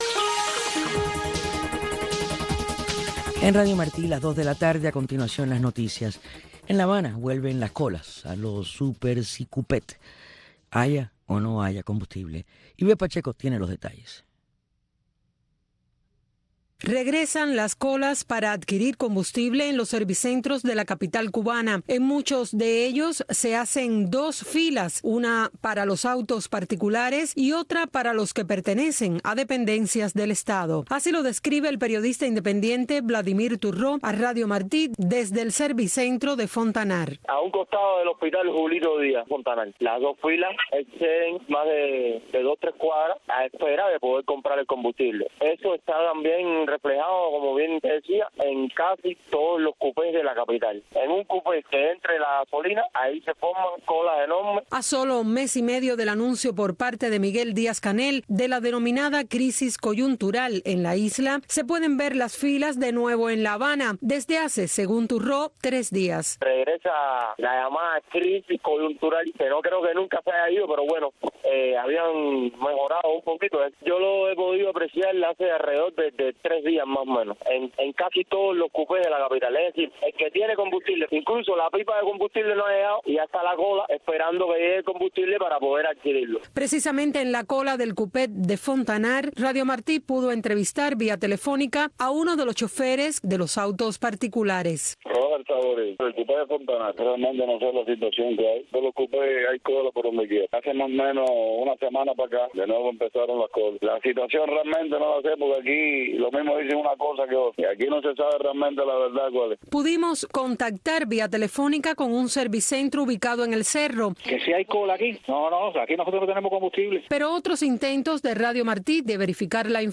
Un espacio informativo con énfasis noticioso en vivo donde se intenta ofrecer un variado flujo de información sobre Cuba, tanto desde la isla, así como desde el exterior.